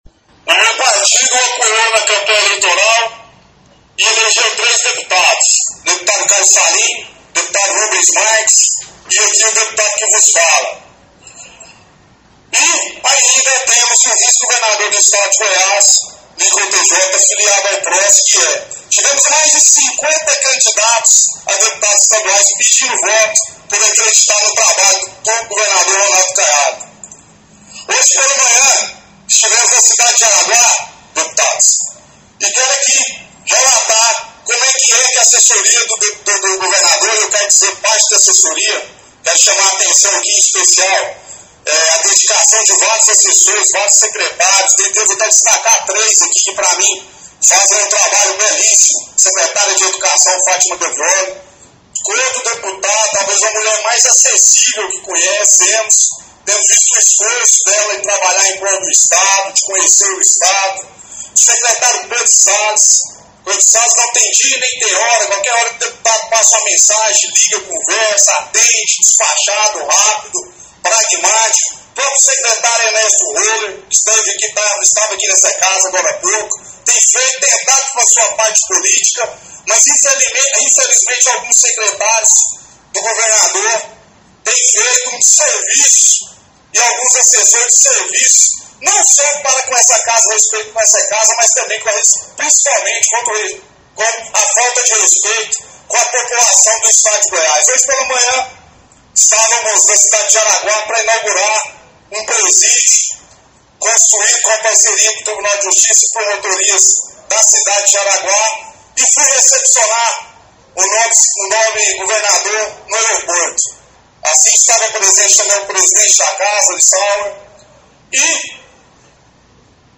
Ouça a declaração do deputado sobre o caso: